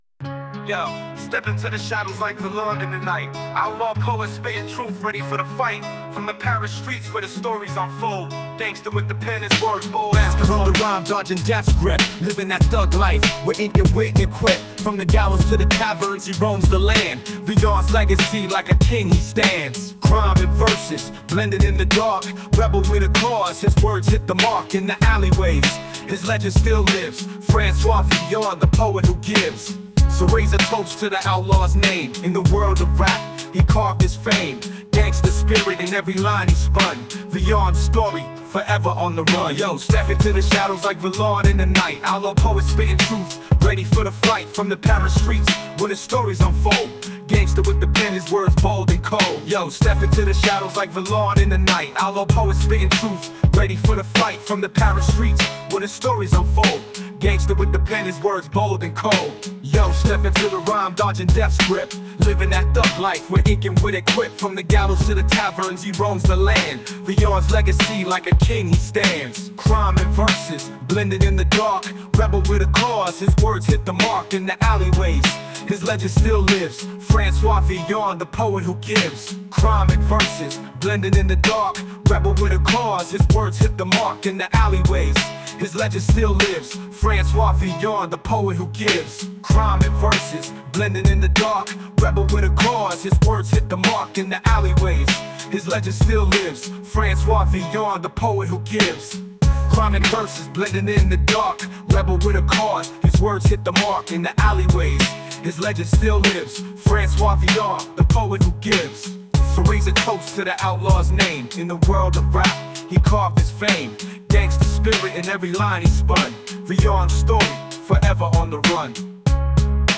Rebel Francois Villon – Gangsta rap